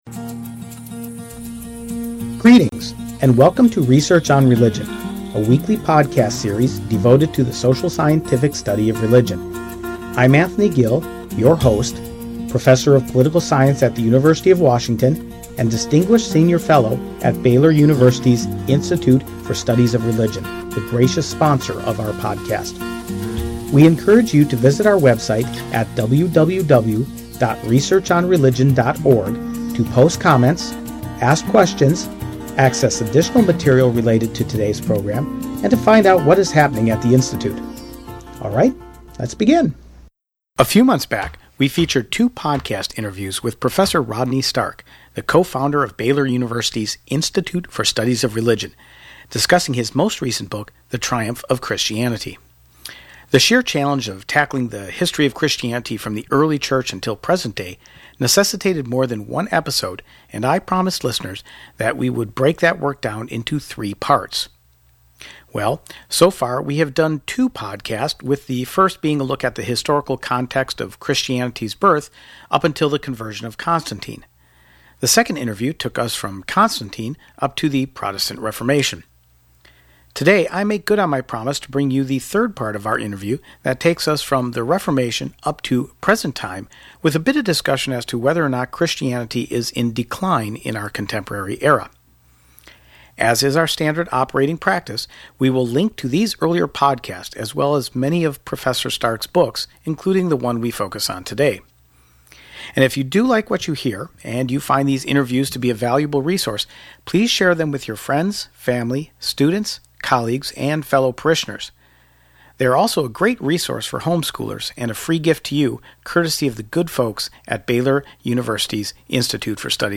Prof. Rodney Stark, co-founder and co-director of Baylor University’s Institute for Studies of Religion, joins us once again to talk about the final portion of his monumental book, The Triumph of Christianity. We pick up the story of Christianity’s growth and change with the Protestant Reformation. Prof. Stark notes that the Reformation wasn’t so much of a reform of the Church as it was a breakaway schismatic sect.